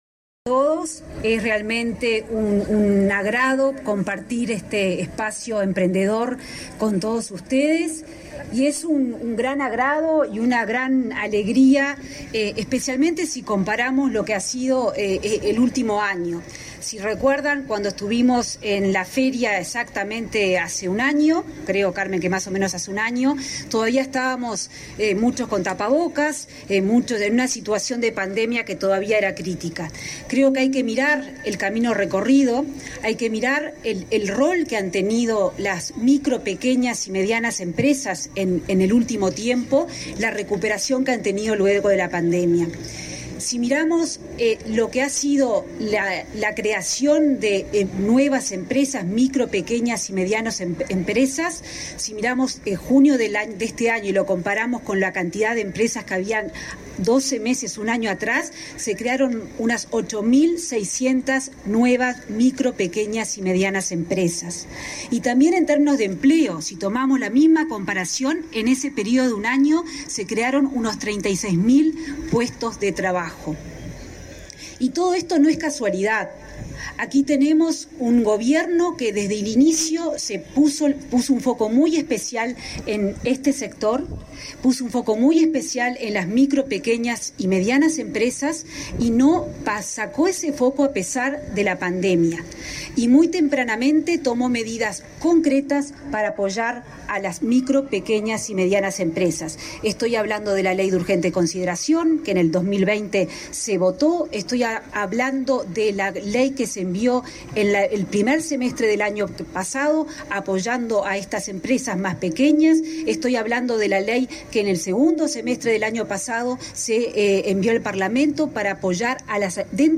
Conferencia de prensa por la segunda edición del Espacio Emprendedor en el marco del Día Nacional de la Cultura Emprendedora
Con la presencia de la vicepresidenta de la República, Beatríz Argimon, se realizó, este 18 de noviembre, la feria de emprendimientos organizada por la Agencia Nacional de Desarrollo (ANDE) y el Parlamento, para conmemorar el Día Nacional de la Cultura Emprendedora. Participaron, además, la ministra de Economía y Finanzas, Azucena Arbeleche, y la presidenta de ANDE, Carmen Sánchez.